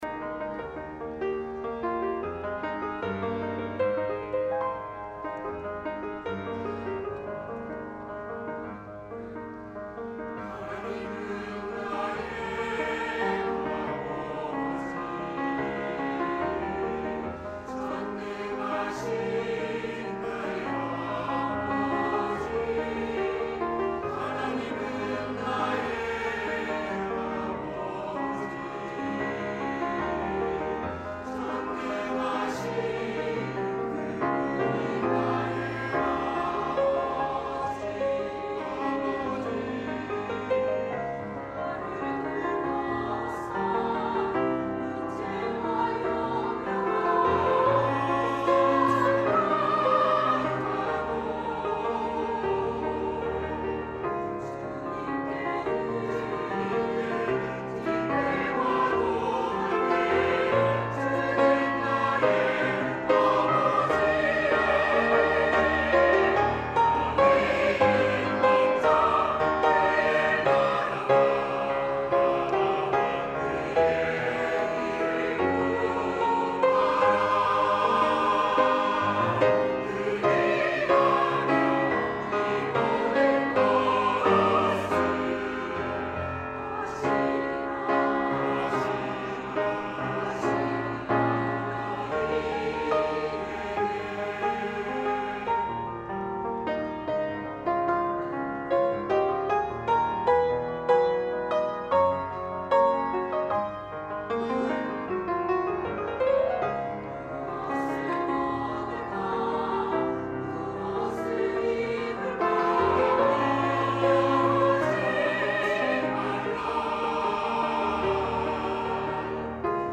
찬양 :: 150301 하나님은 나의 아버지
시온찬양대